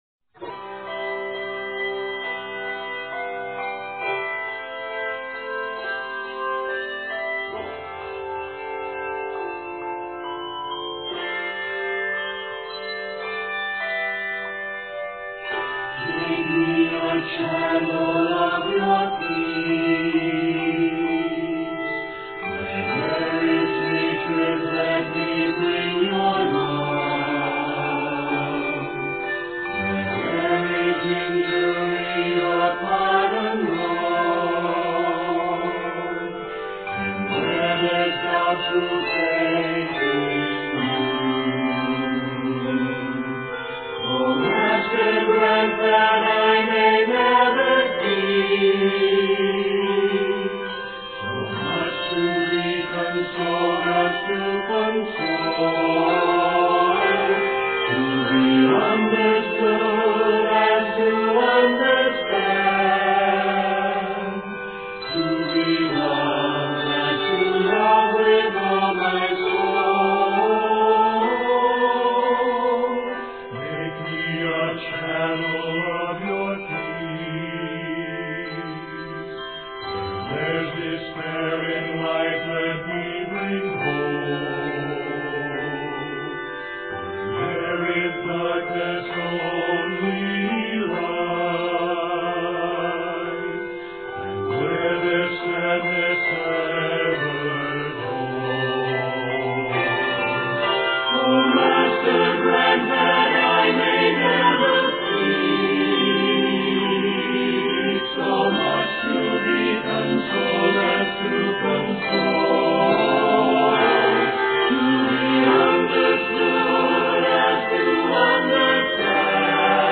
Three-five octaves of handbells
Arranged in C Major and F Major, this piece is 57 measures.